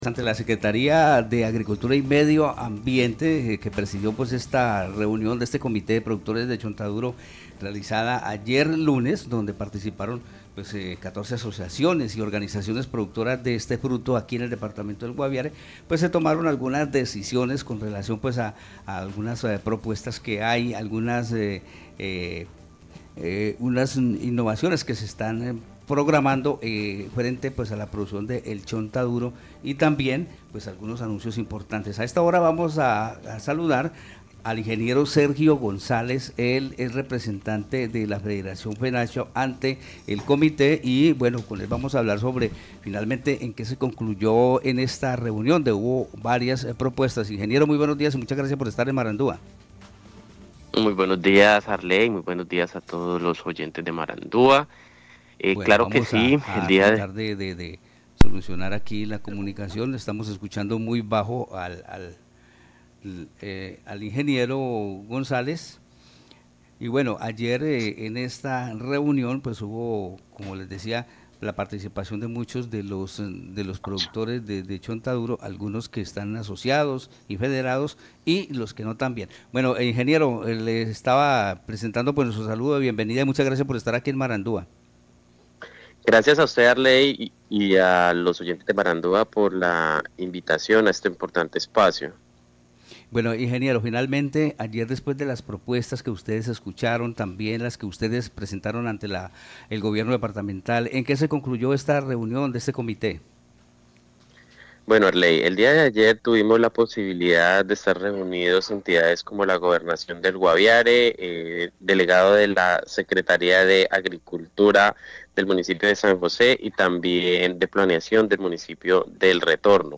manifestó en entrevista con Marandúa Noticias que una de las propuestas más relevantes fue la formalización al 100% de la cadena productiva del chontaduro en Guaviare.